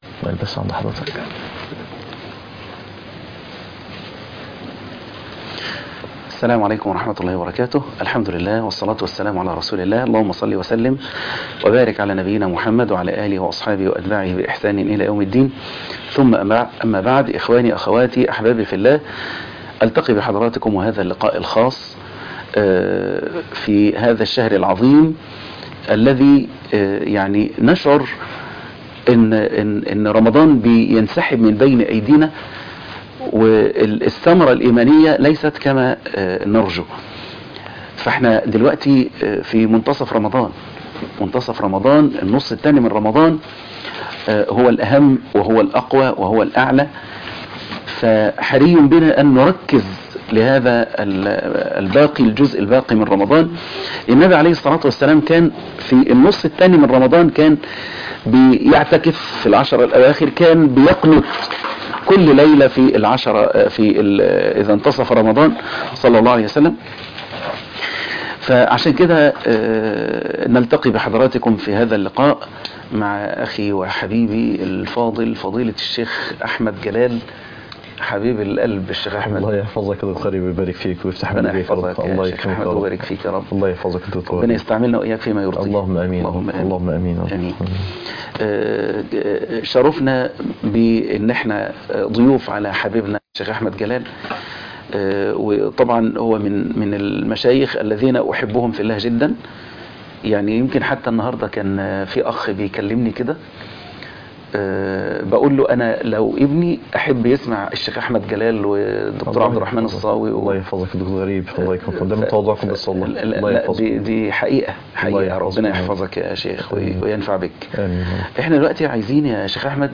كيف تعيش رمضان- بث مباشر